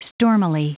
Prononciation du mot stormily en anglais (fichier audio)
Prononciation du mot : stormily